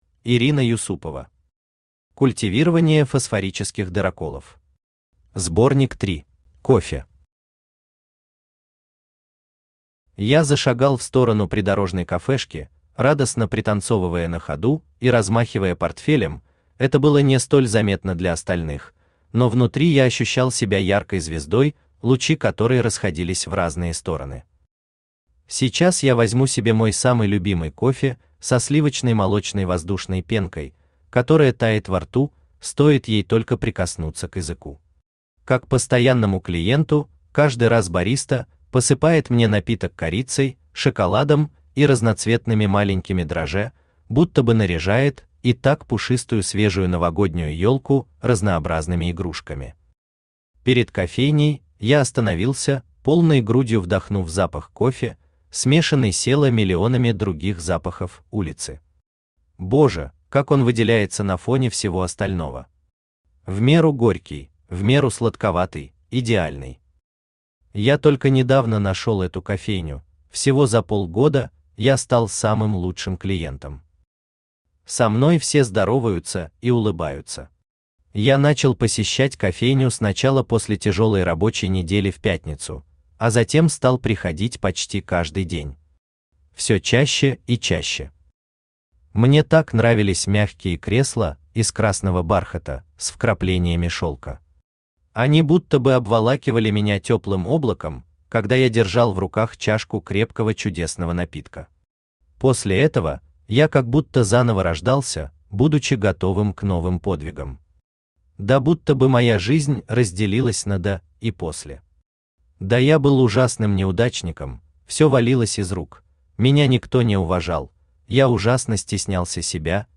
Аудиокнига Культивирование фосфорических дыроколов. Сборник 3 | Библиотека аудиокниг
Сборник 3 Автор Ирина Алексеевна Юсупова Читает аудиокнигу Авточтец ЛитРес.